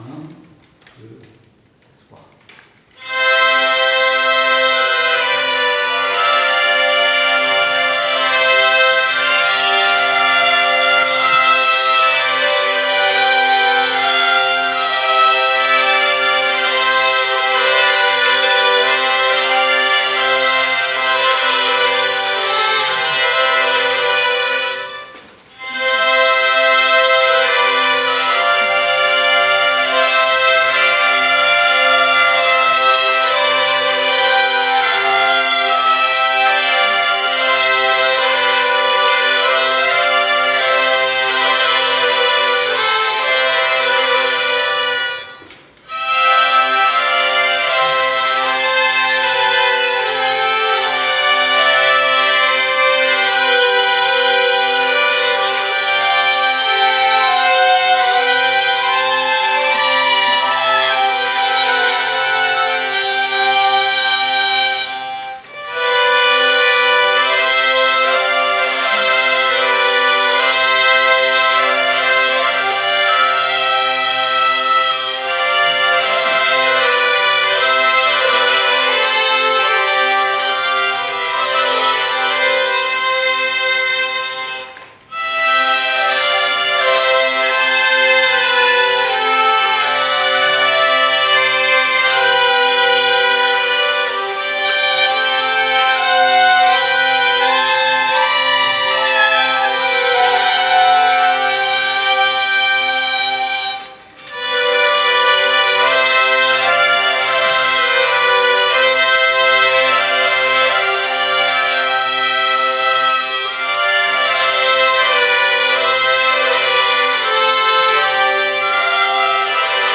Programme des auditions
Atelier vielles